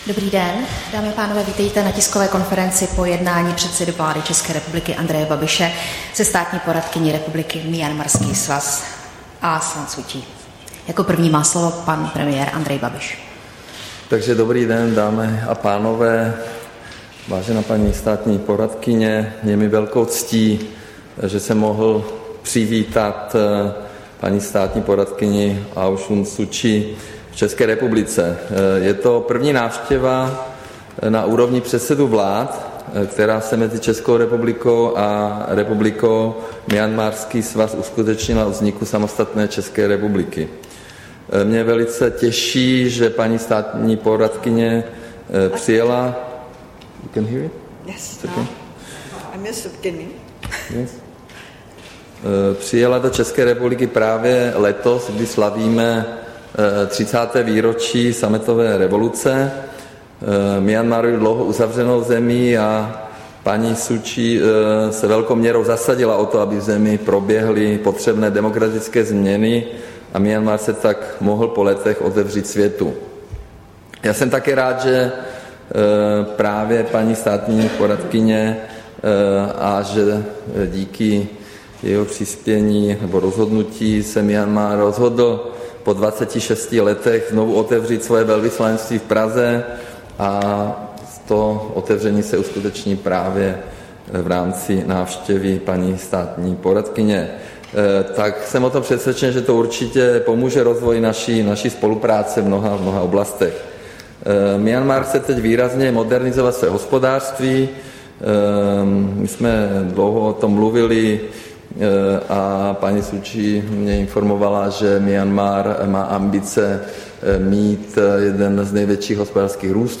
Tisková konference po jednání se státní poradkyní Myanmaru Su Ťij, 3. června 2019